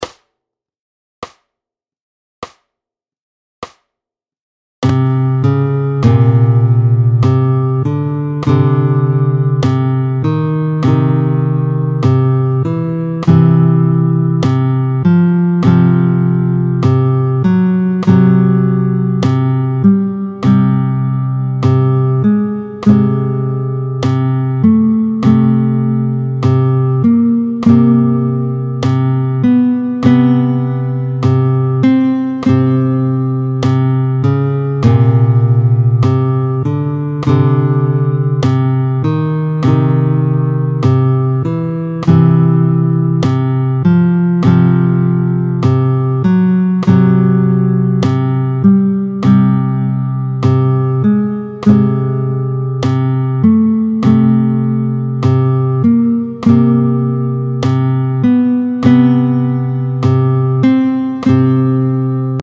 11-01 Les différents intervalles, tempo 50